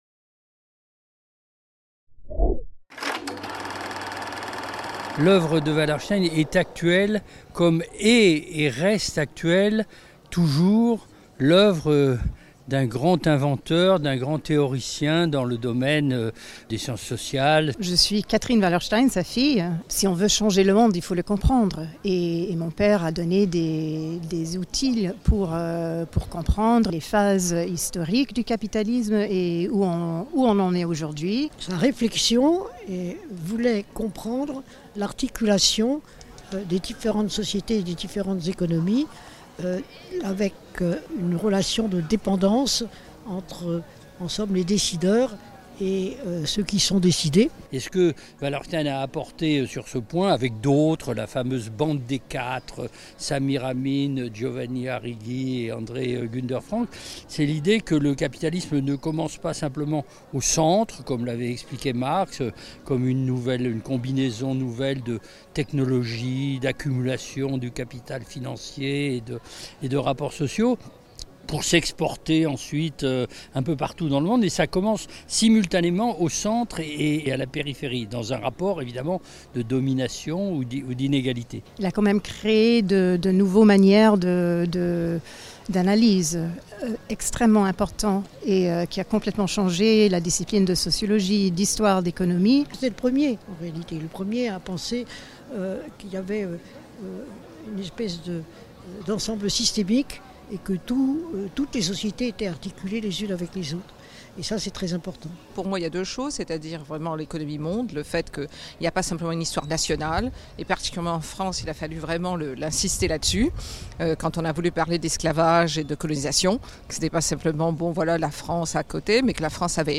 Autour de l'oeuvre d'Immanuel Wallerstein - Interviews | Canal U
Le colloque Capitalisme, anticapitalisme et sciences sociales engagées à l'échelle globale : autour de l’œuvre d'Immanuel Wallerstein qui s'est tenu le 11 et 12 septembre 2023, a donné lieu à plusieurs interviews des intervenants et organisateurs.